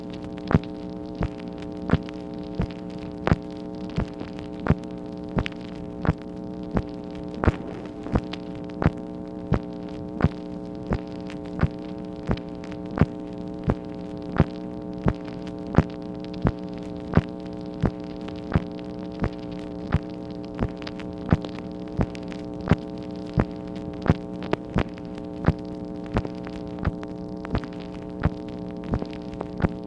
MACHINE NOISE, September 24, 1964
Secret White House Tapes | Lyndon B. Johnson Presidency